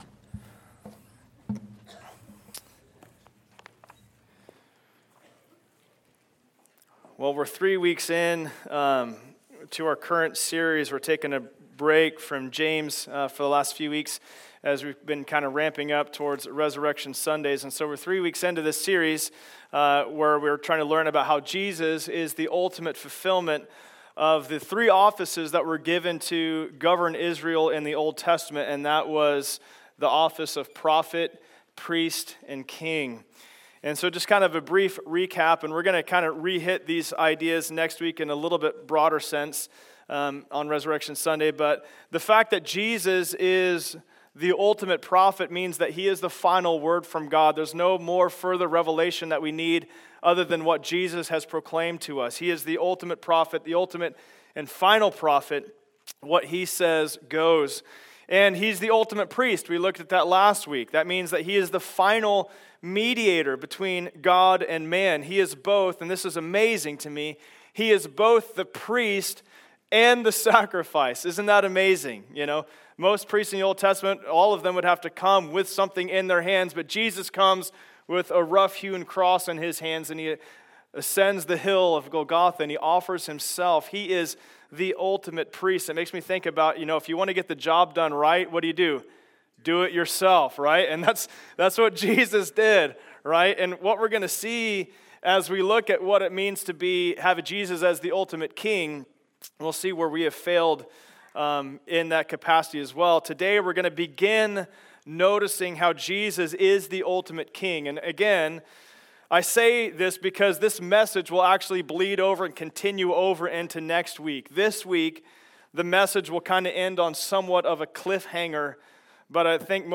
King Service Type: Sunday Service Download Files Notes « Jesus